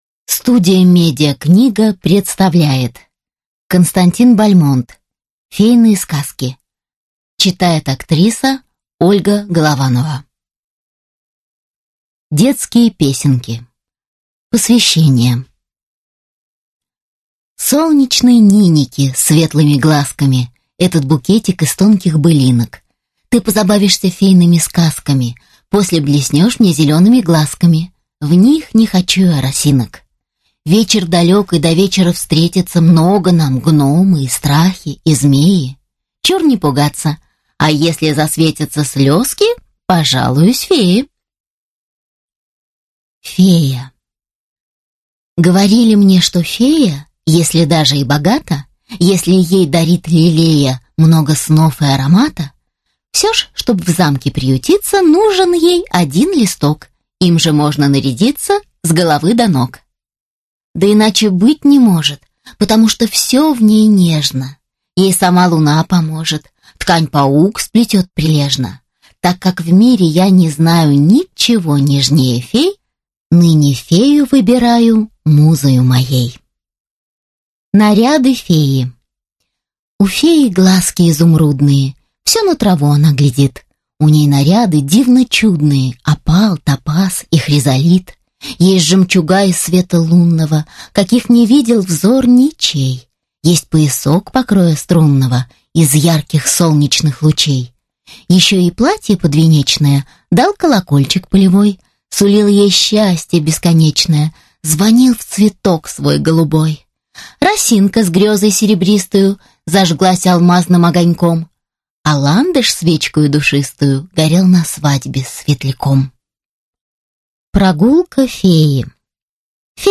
Аудиокнига Фейные сказки